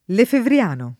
lefevriano [ lefevr L# no ] (meglio che lefebvriano [ id. ])